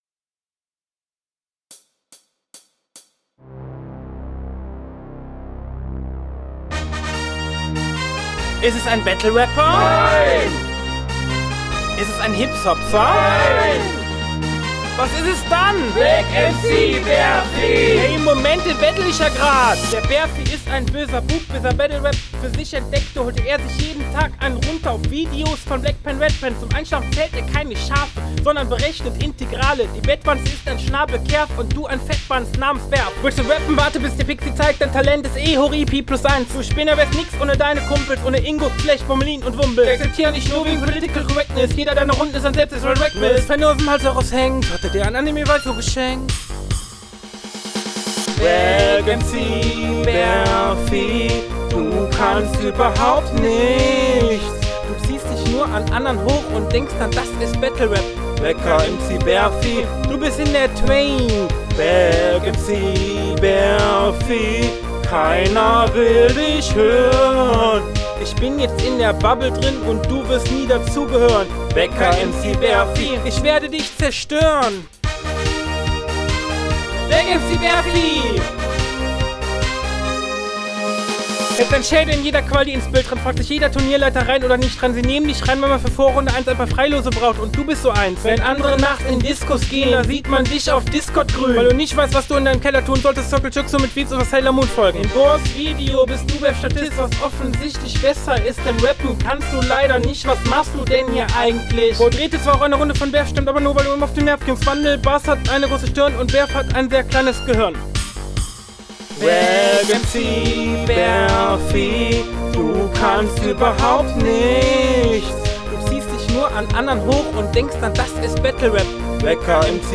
Flow: Ziemlich Abgehackt und viele Stellen vernuschelt, du steigerst dich immer weiter, aber, das war …
Text: Hängst vorne und hinten mit dem Takt Soundqualität: sehr schwach bitte …